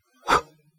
blow.ogg